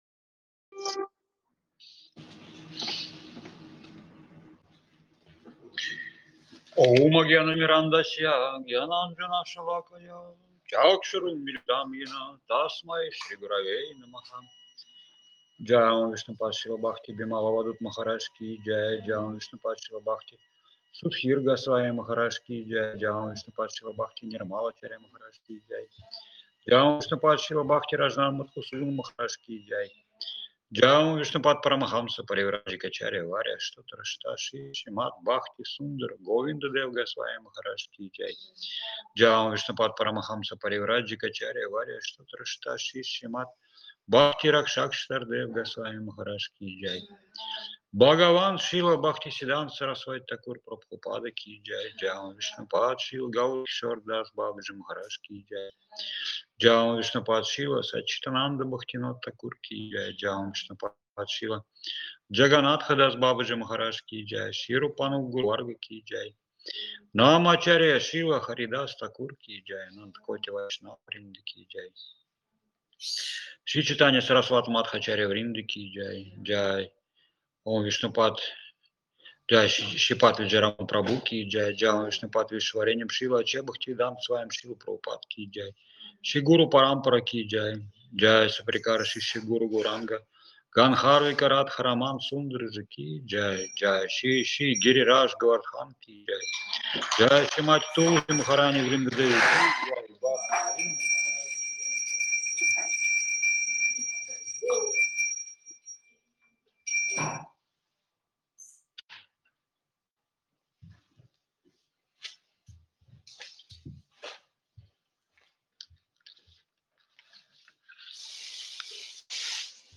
Место: Кисельный (Москва)
Лекции полностью